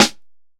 DillaSnapSnare.wav